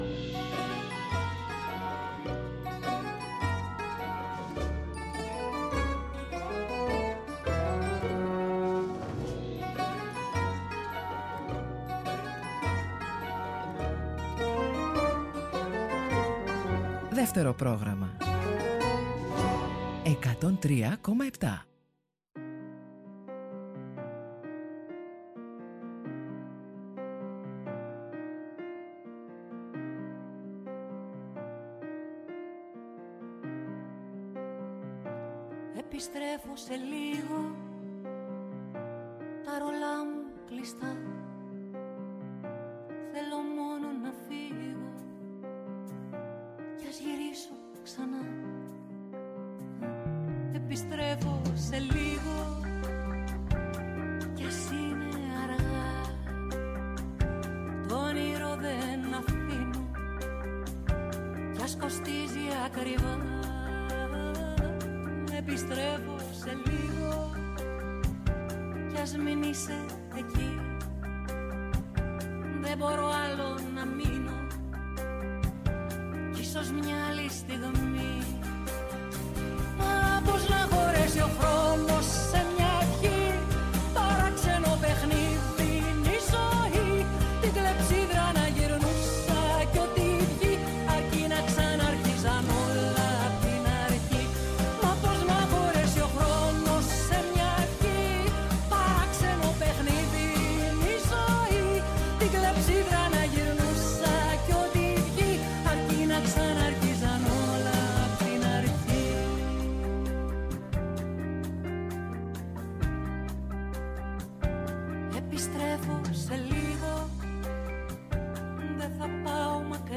Μουσικές, τραγούδια, θετικές σκέψεις, χρηστικές και χρήσιμες ειδήσεις, χαρούμενη γνώση που έλεγε και ο Φρίντριχ Νίτσε στο ομώνυμο βιβλίο του και φυσικά καλή διάθεση. ΔΕΥΤΕΡΟ ΠΡΟΓΡΑΜΜΑ